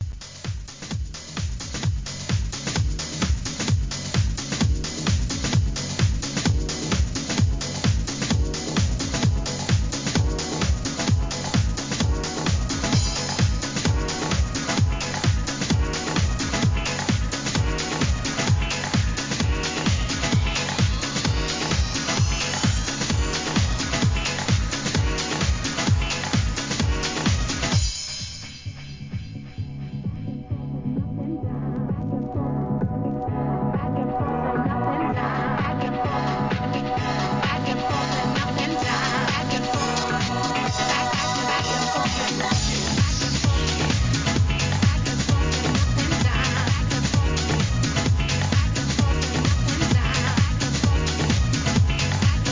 SOUL/FUNK/etc...
人気ブートリミックス・シリーズ